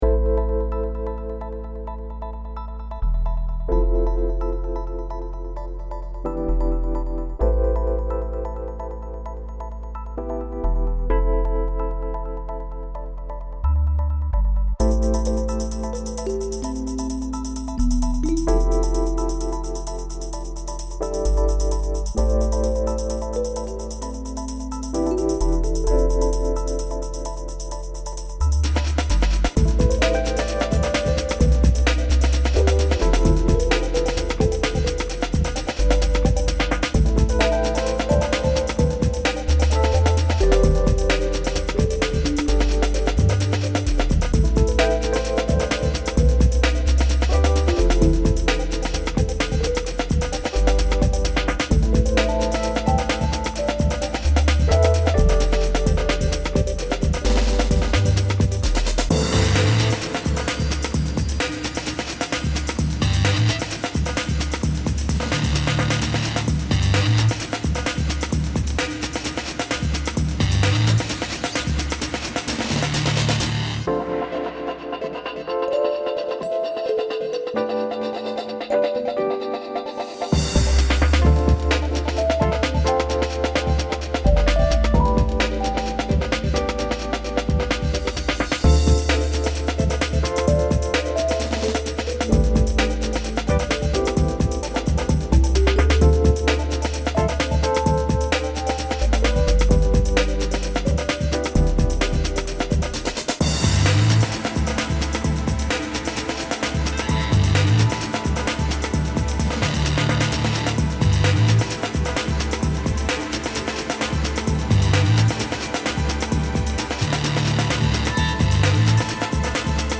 Download Song - Break Beat / Ambient Drum 'n Bass